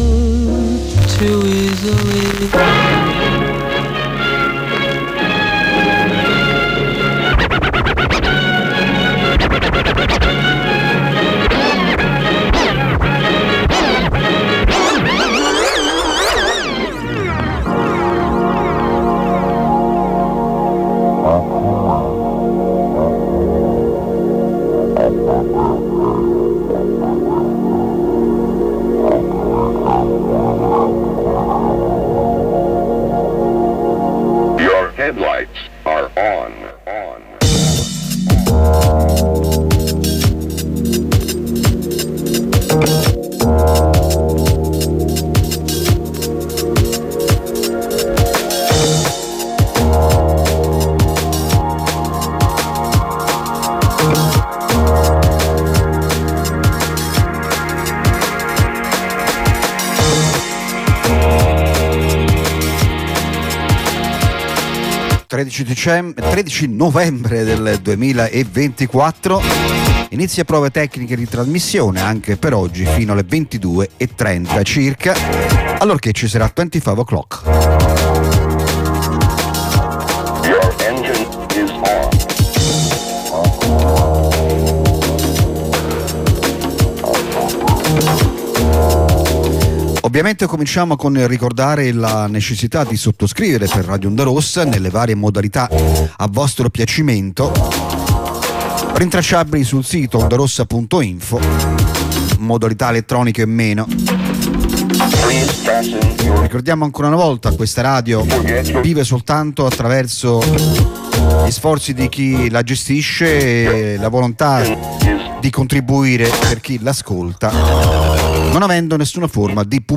Industrial;Ambient;Noise(10577) | Radio Onda Rossa